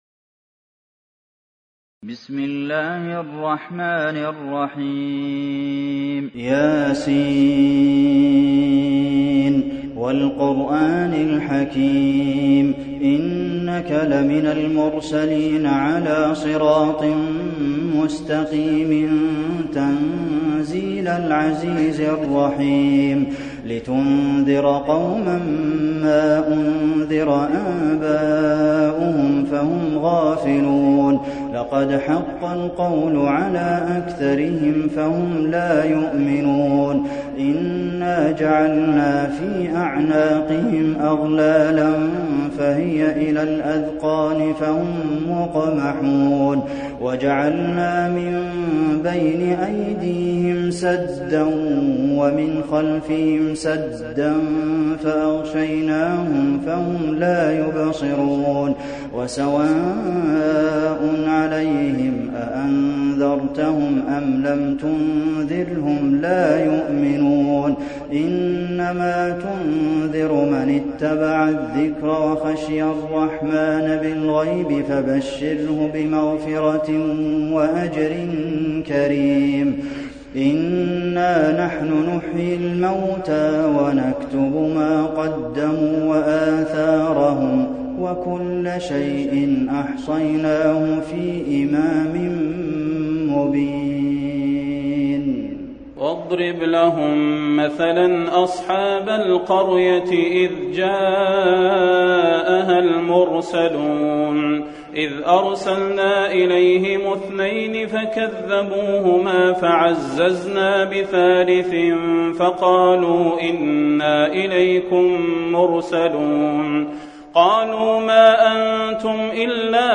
المكان: المسجد النبوي يس